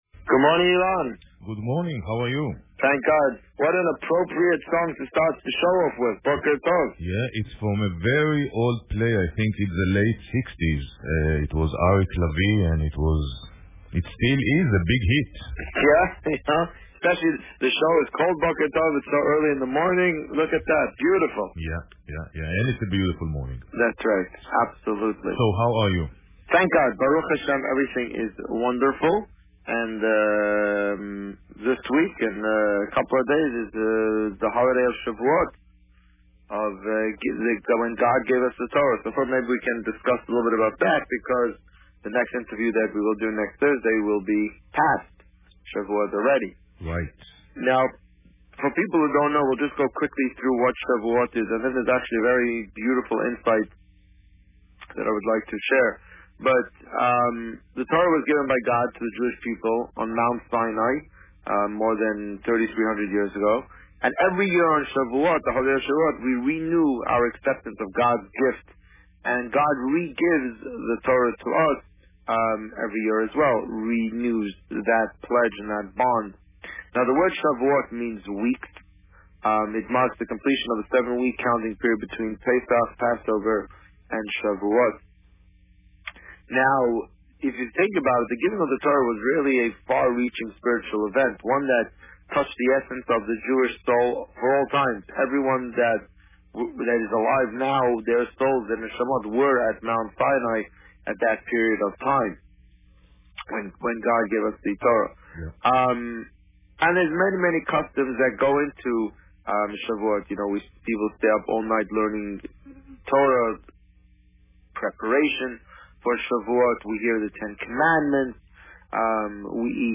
Discussion about Shavuot